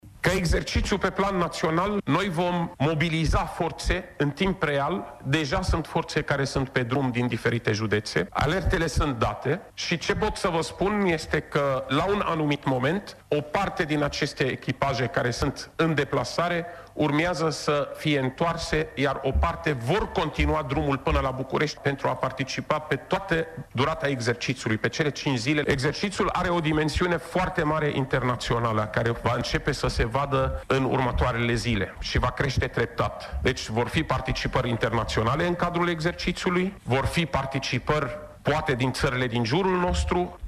Secretarul de stat în MAI, Raed Arafat a declarat că exerciţiul naţional ‘Seism 2018’, care a fost declanşat astăzi are ca menire testarea concepţiei de răspuns la cutremur, modul în care reacţionează fiecare dintre judeţele ţării şi modul în care reacţionează autorităţile: